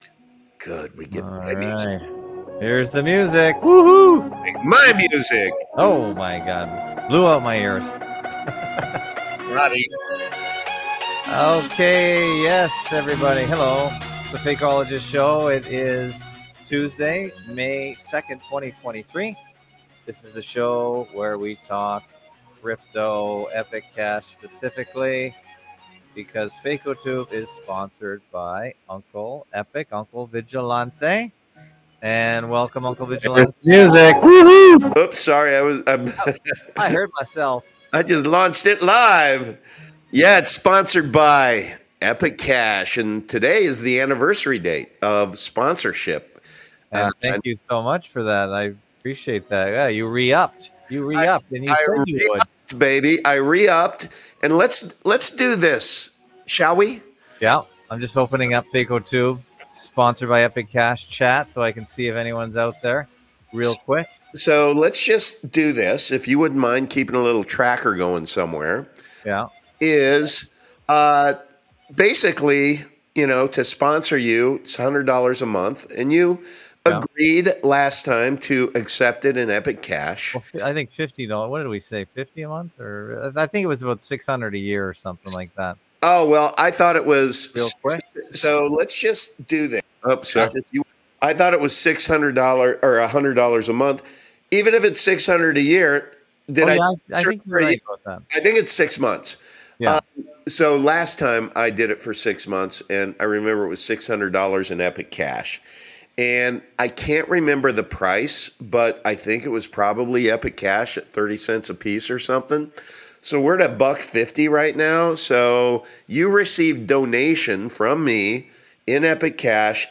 Fakeologist show Live Sun-Thu 830pm-900pm EDT